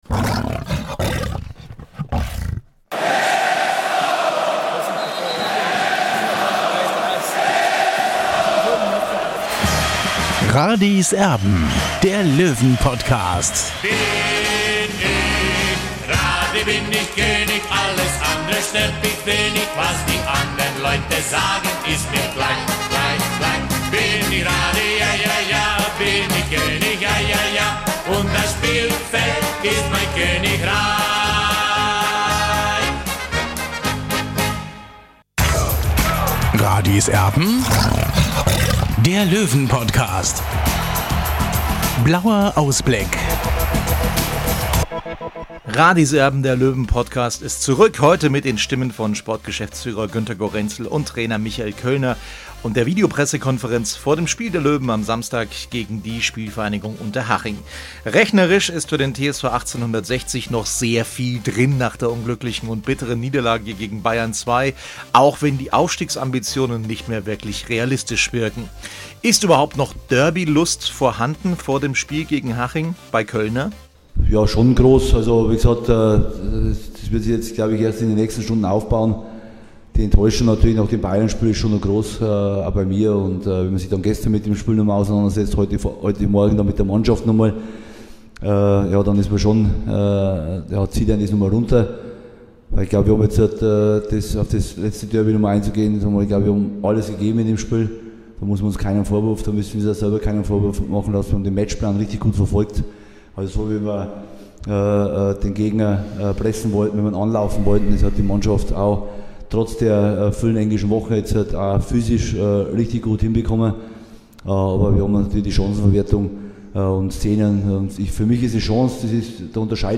Darüber spricht der Oberpfälzer in der Spieltags-PK vor dem Derby gegen Unterhaching.